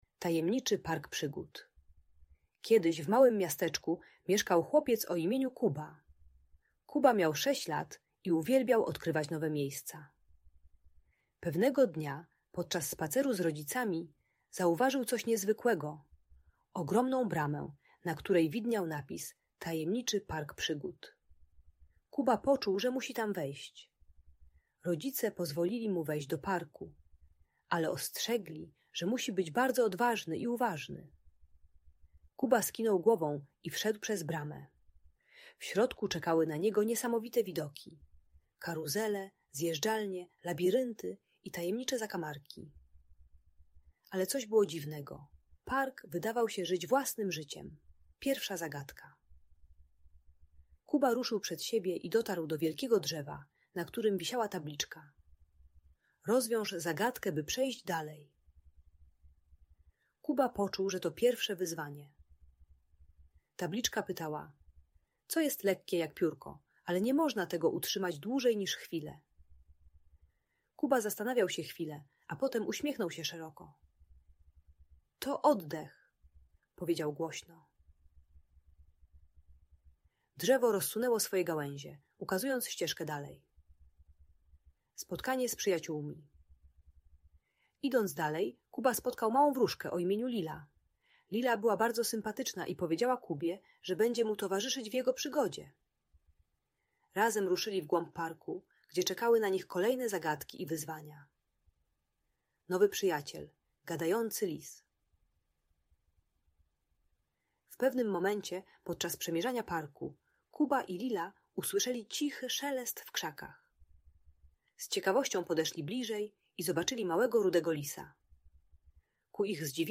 Tajemniczy Park Przygód - Audiobajka